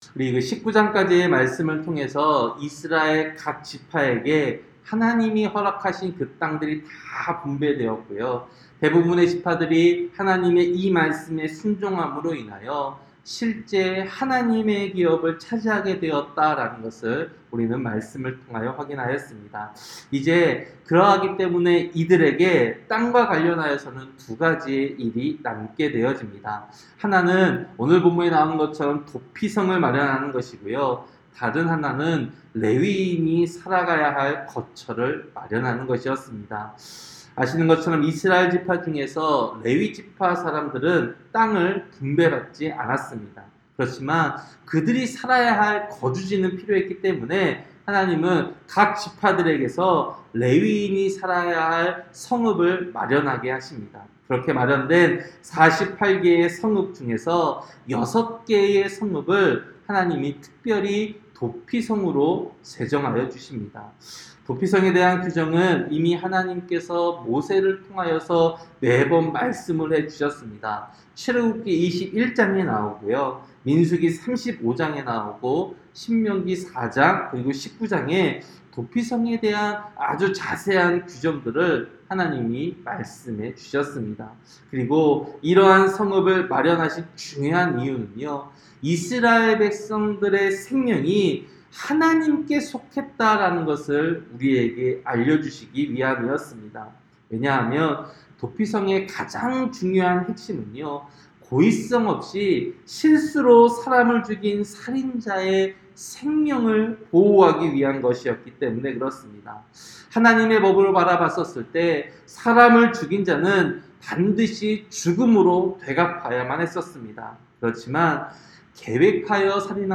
새벽설교-여호수아 20장